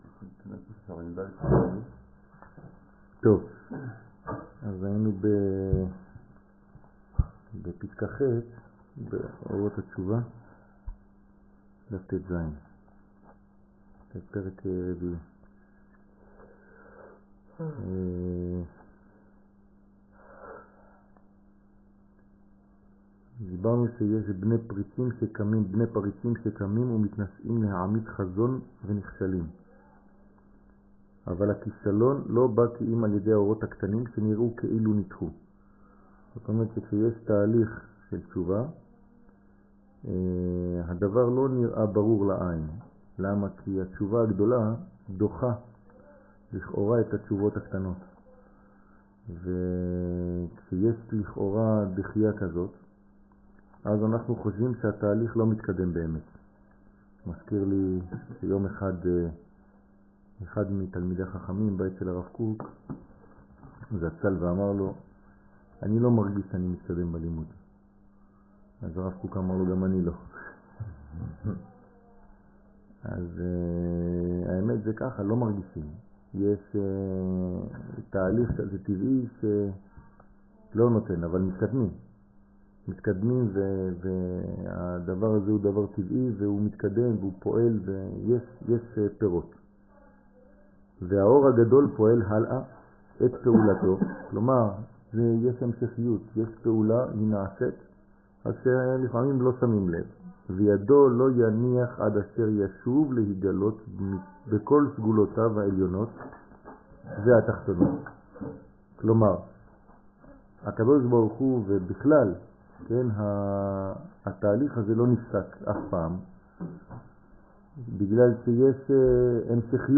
אורות התשובה זהות שיעור מ 20 דצמבר 2015 24MIN הורדה בקובץ אודיו MP3 (22.77 Mo) הורדה בקובץ אודיו M4A (2.96 Mo) TAGS : לימוד על הגאולה אמונה אורות התשובה עברית שיעורים תורה וזהות הישראלי שיעורים קצרים